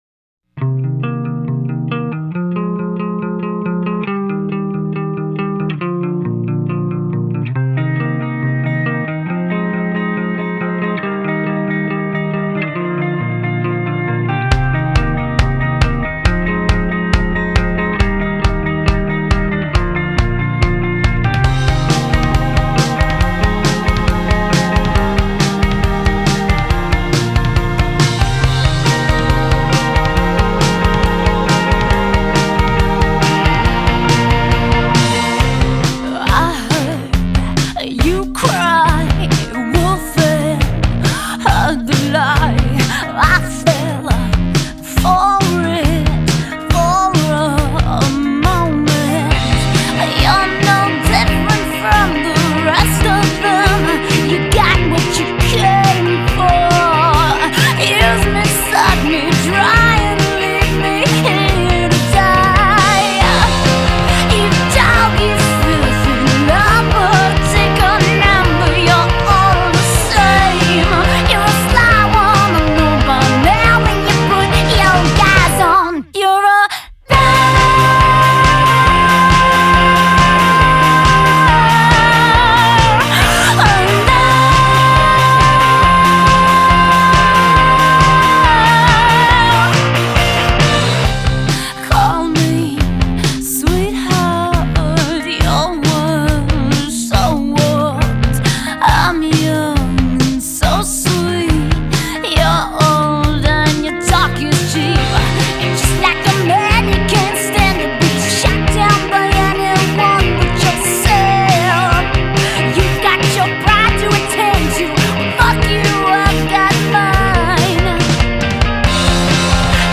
Punk
Glam trash